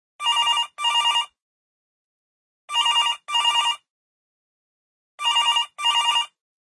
ring.mp3